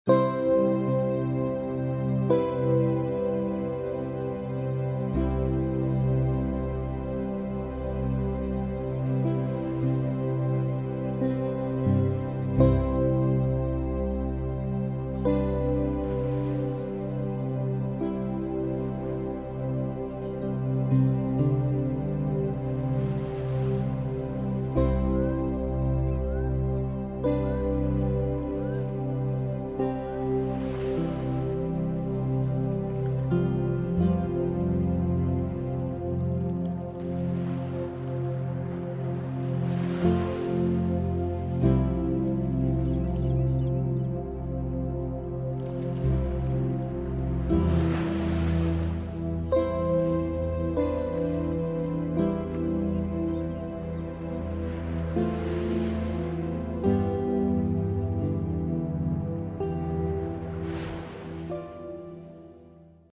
ביטחון עצמי לילדים מוזיקה ורחש גלי ים